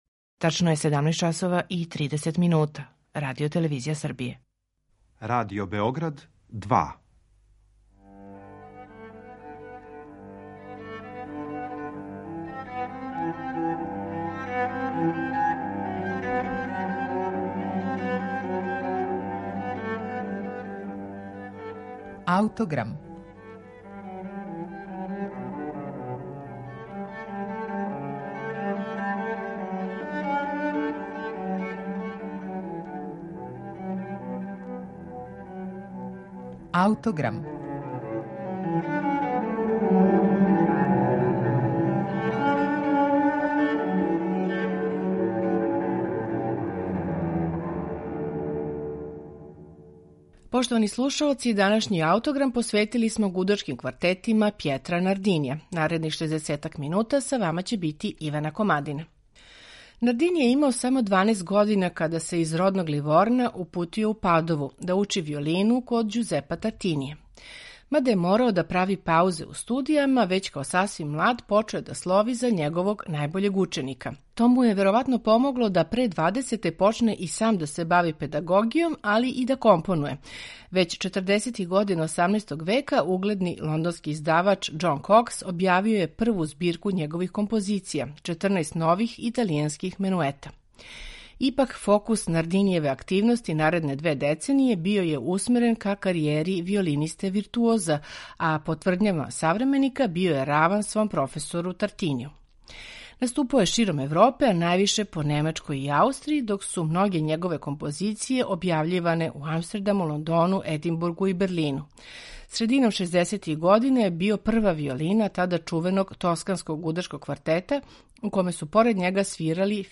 Пјетро Нардини: Гудачки квартети
По стилским одликама припадају његовом фирентинском периоду стваралаштва. У данашњем Аутограму Нардинијеве гудачке квартете слушамо у интерпретацији квартета Eleusi.